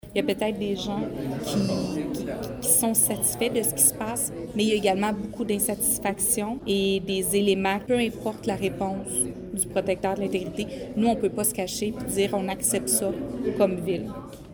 De son côté, la mairesse, Julie Bourdon, explique que la décision est franche et que certains éléments sont déterminants :